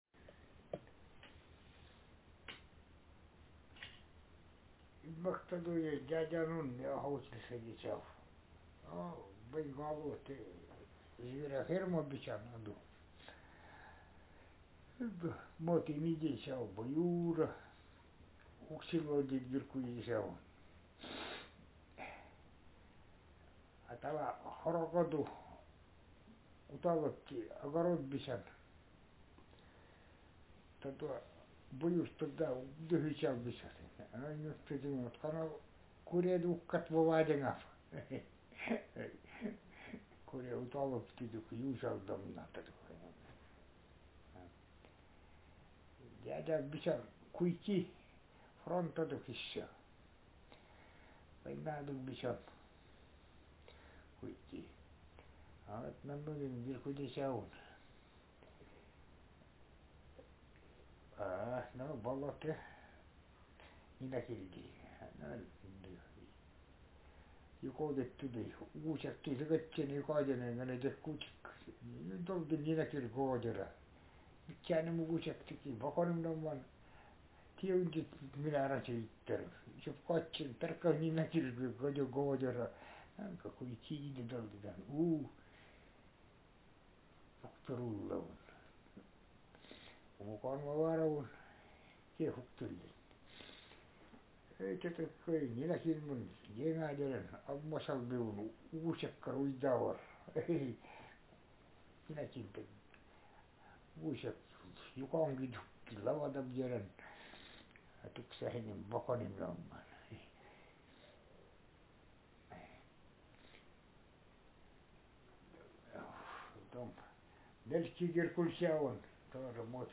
Speaker sexm
Text genrepersonal narrative